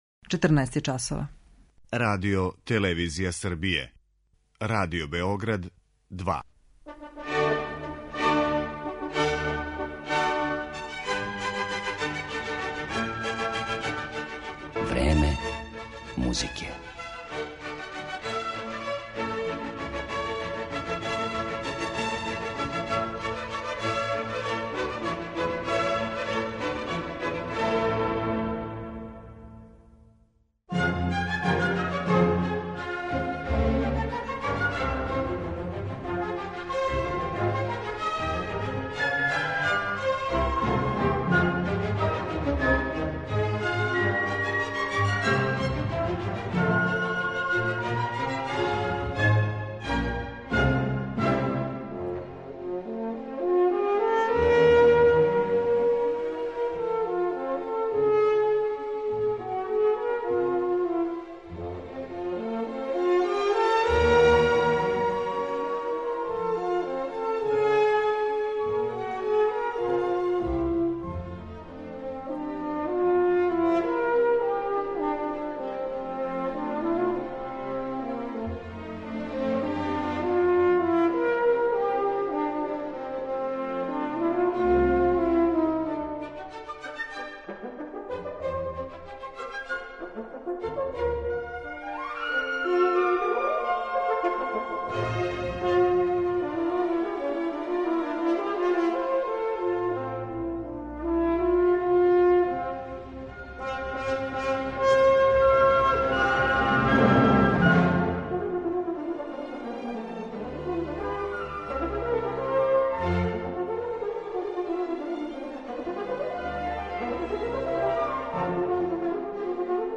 Један од технички најзахтевнијих дувачких инструмента јесте хорна, а један од најбољих извођеча на њој био је Херман Бауман, коме је посвећена данашња емисија.